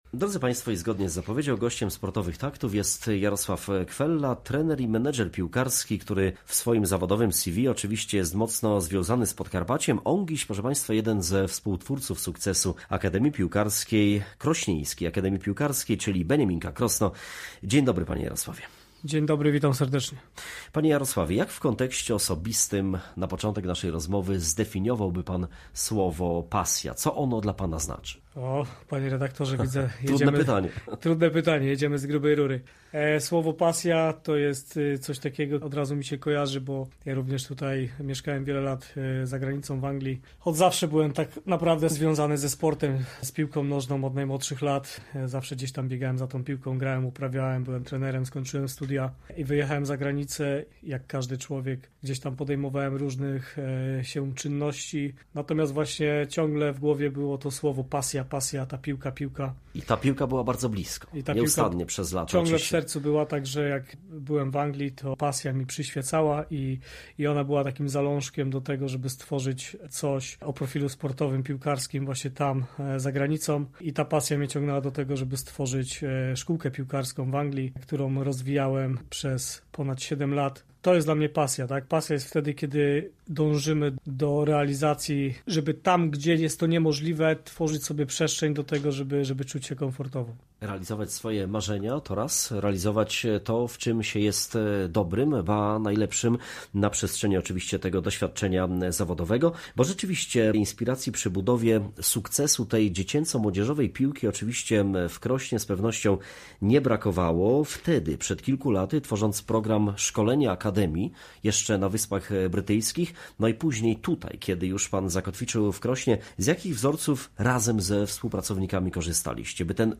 Gościem sobotniej audycji „Sportowe Takty”